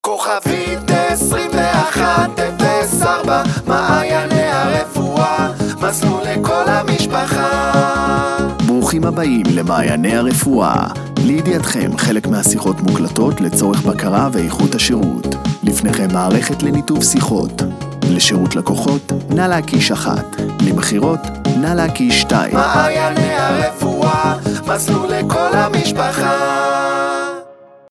ג’ינגלים מוזיקליים
ג'ינגל מוזיקלי כולל לחן ושירה - עד 60 שניות